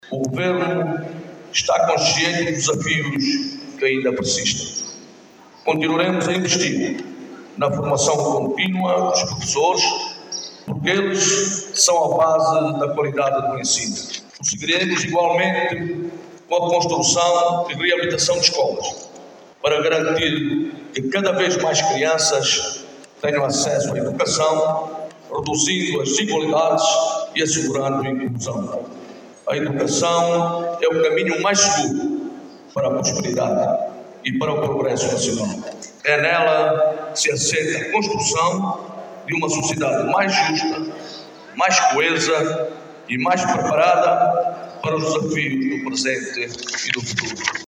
O ano lectivo de 2025/2026 foi aberto hoje,  segunda-feira, 01 de Setembro, em Luanda, com o Governo a destacar o compromisso de colocar a educação no centro das prioridades nacionais.
Na ocasião, o Governador de Luanda Luís Nunes, destacou investimentos na formação contínua de professores, bem como na reabilitação e construção de novas infraestruturas, para garantir que mais crianças tenham acesso à educação de qualidade e que o sector continue a ser motor do desenvolvimento do país.